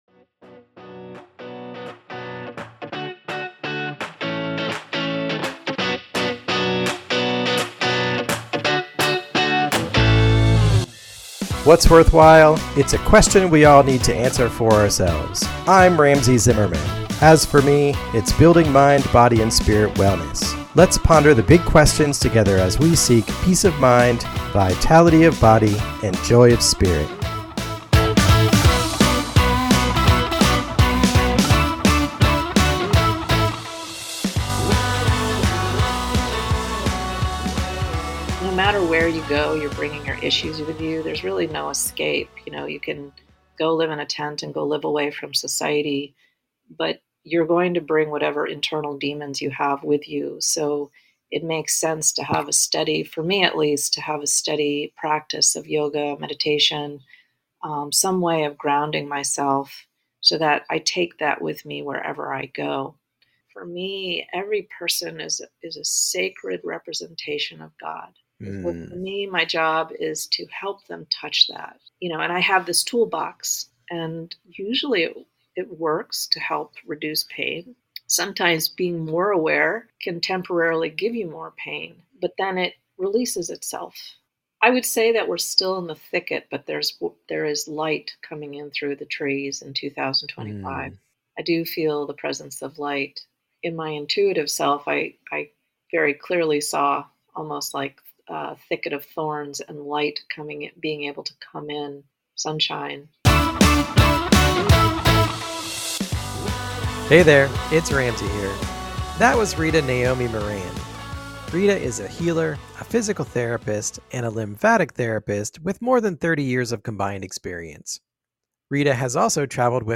Tune in for an inspiring, spiritual and wide-ranging conversation.